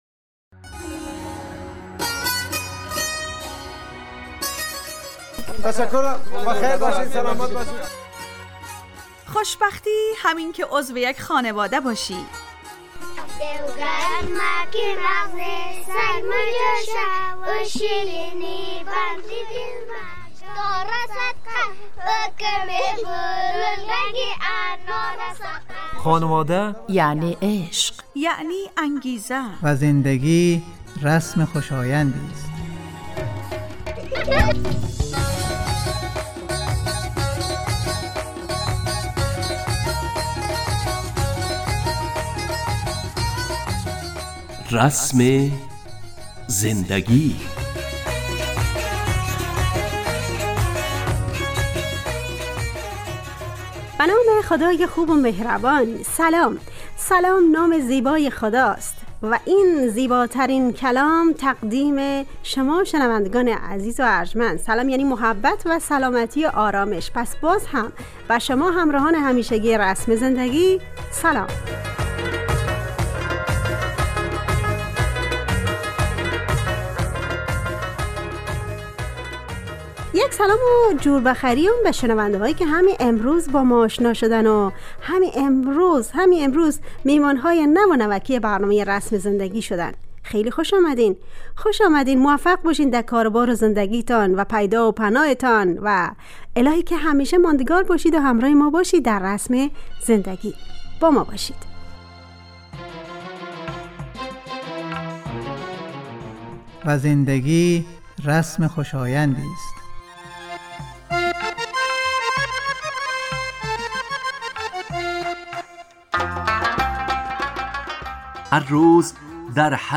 رسم زندگی_ برنامه خانواده رادیو دری ___ چهار شنبه 12 آذر 404 ___ خبر و خبرچینی خبرگیری _ گوینده و تهیه کننده و میکس